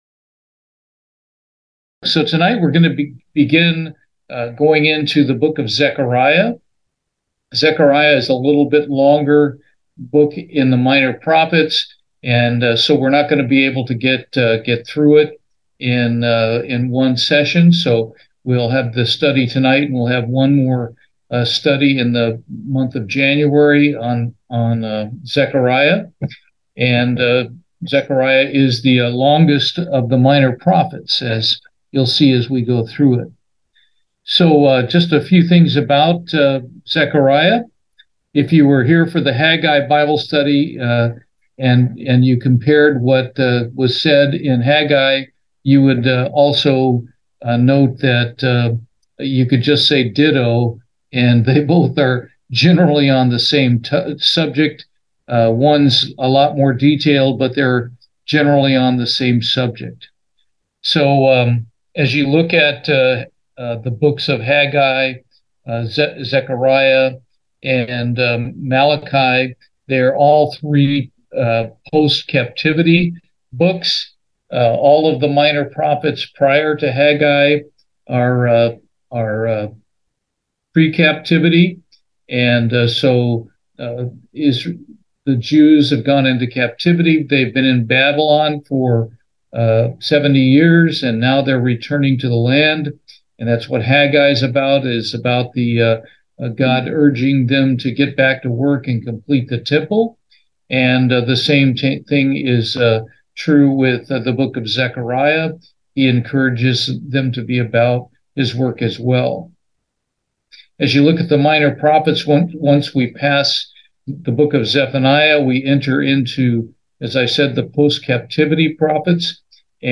Bible Study, Zechariah, Part 1